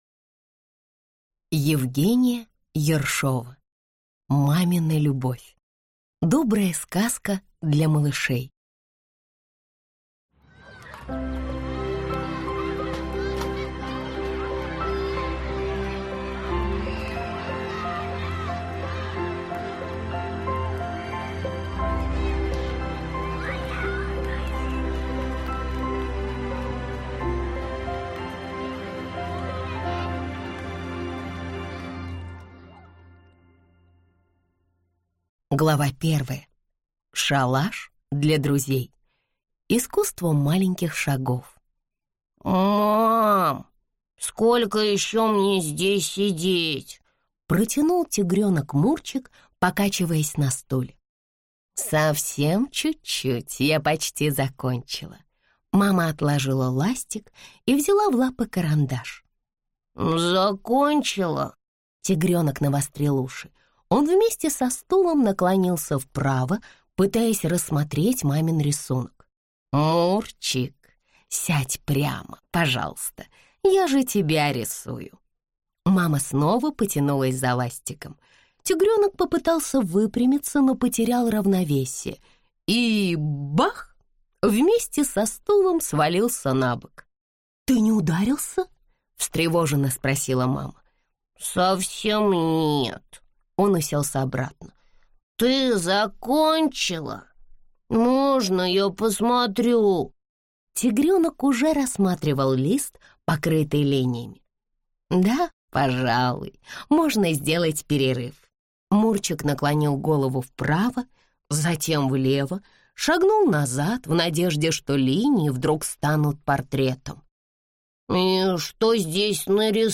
Аудиокнига Мамина любовь. Добрая сказка для малышей | Библиотека аудиокниг